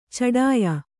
♪ caḍāya